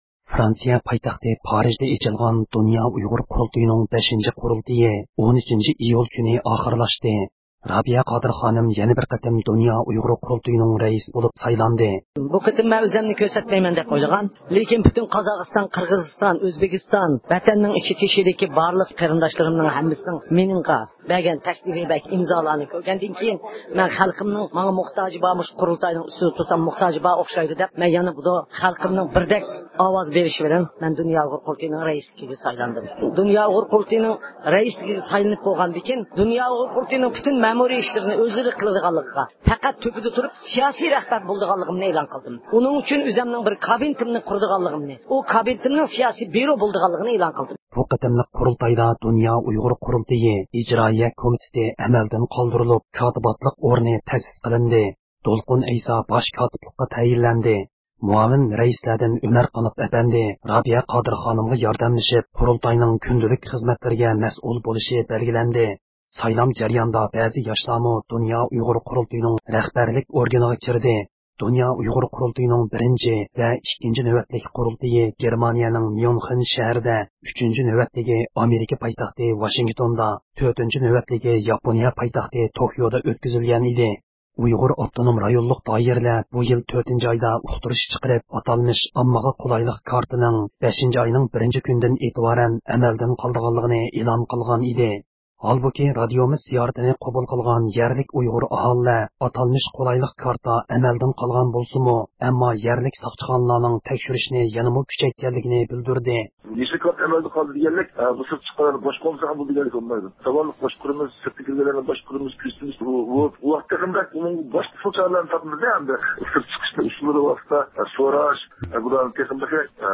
erkin-asiya-radiosi-yengi.jpgئەركىن ئاسىيا رادىئوسى ئۇيغۇر بۆلۈمى ھەپتىلىك خەۋەرلىرى